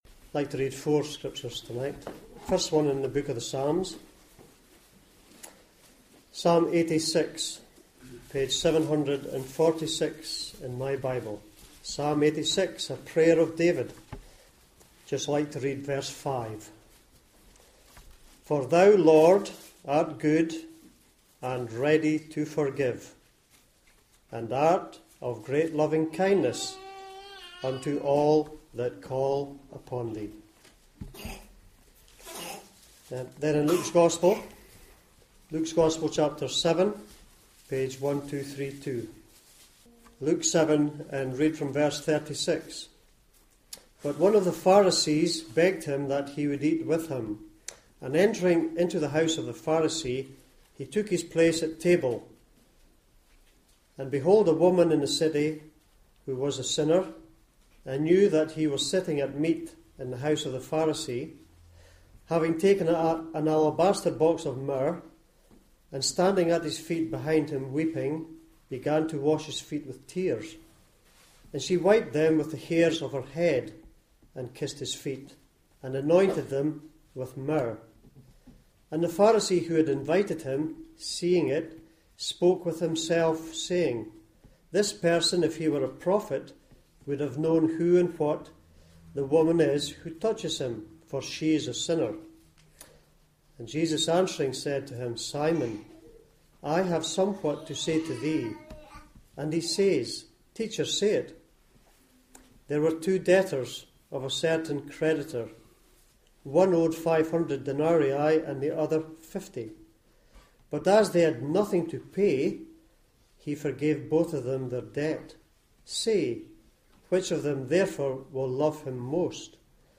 Gospel Preachings